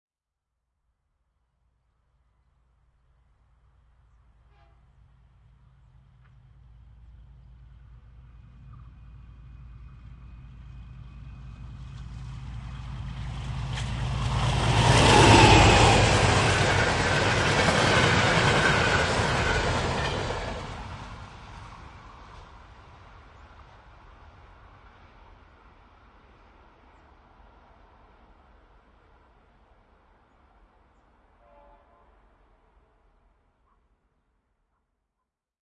出发的货运列车
描述：一辆货车拉到我家门外！简单的霰弹枪麦克风单声道录音。
标签： 环境 现场录音 货运列车 环境